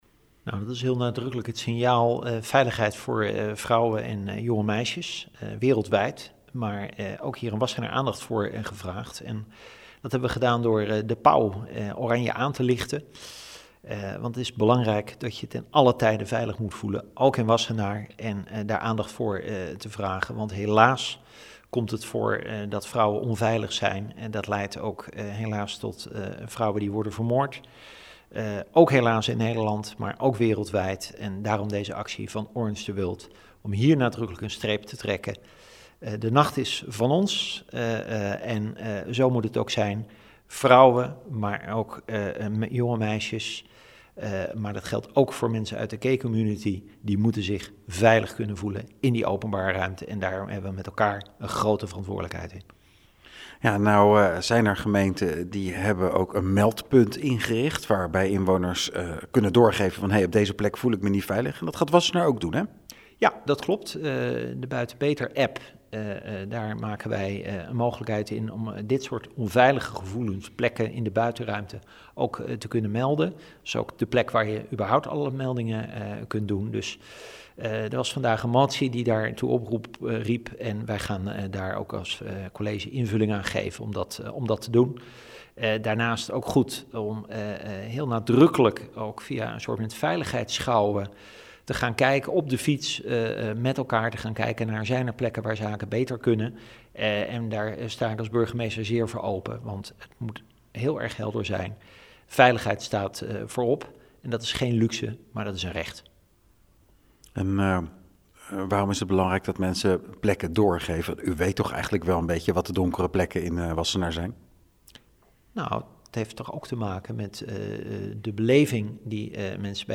Burgemeester Leendert de Lange over Orange the World in Wassenaar: